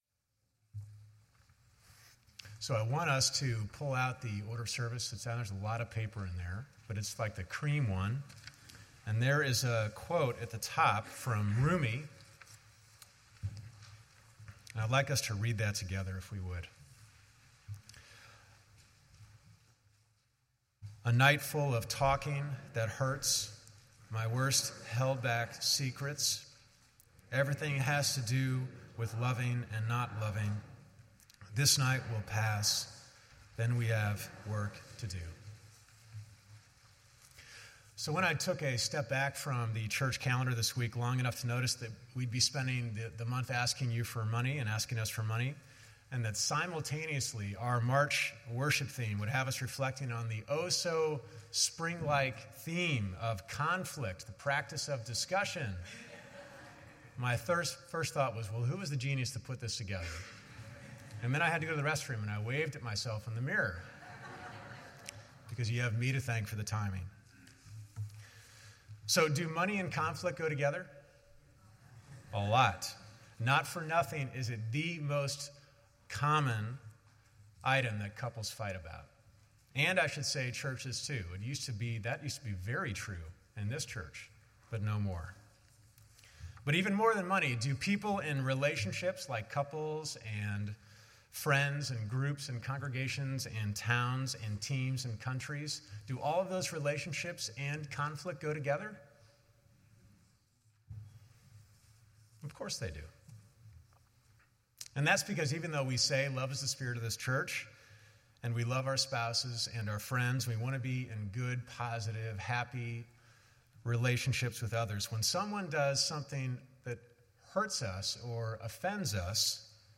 A sermon recognizing the difficulty in moving past anger and blame to honest, loving communications.